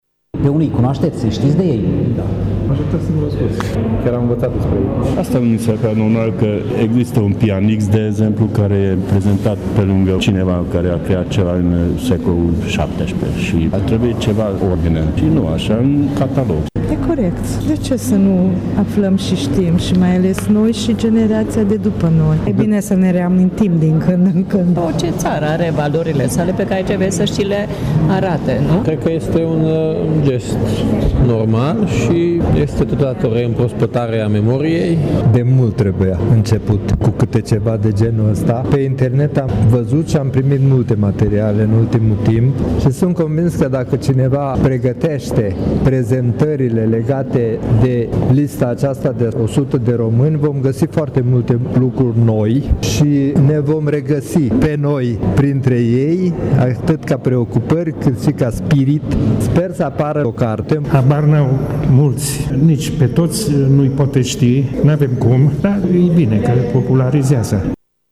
Propunerea a fost inclusă într-o expoziție panouri fotografice deschisă ieri în foaierul Palatului Culturii din Tîrgu-Mureș, cu prilejul Zilei Culturii Naționale. Târgumureșenii cu care am stat de vorbă au apreciat mult ideea, și pentru că unele din personalitățile expuse nu sunt foarte cunoscute marelui public.